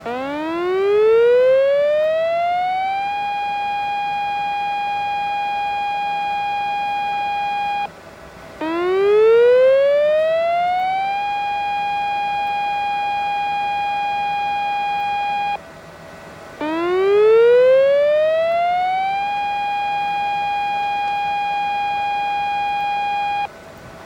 サイレン（警報音）の種類
「サイレン7秒→1秒休み」を3回くり返します。
【警戒レベル5・4】7秒吹鳴1秒休み (音声ファイル: 375.1KB)